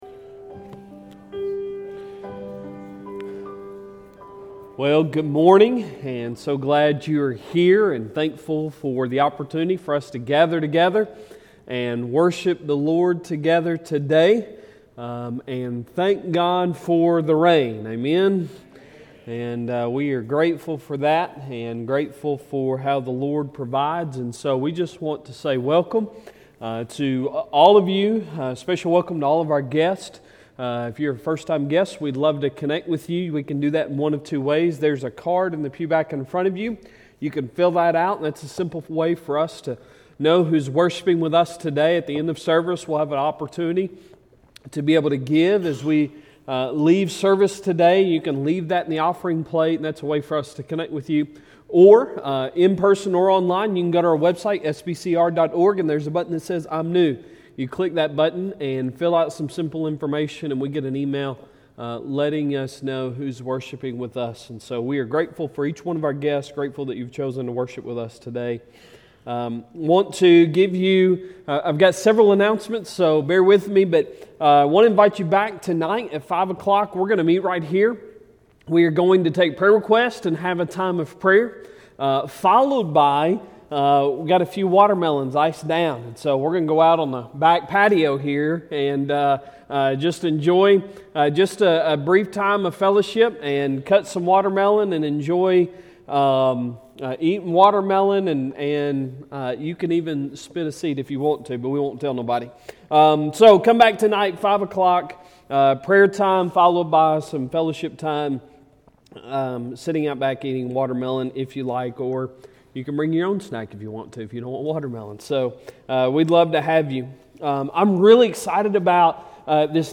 Sunday Sermon July 31, 2022